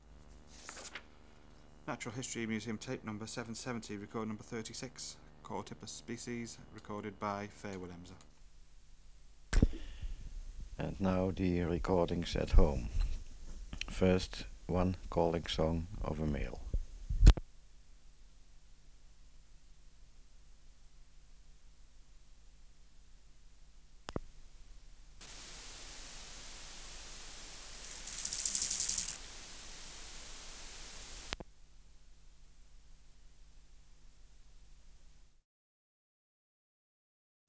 591:36 Chorthippus sp. (770r36) | BioAcoustica
Original Verbatim Species: Chorthippus sp.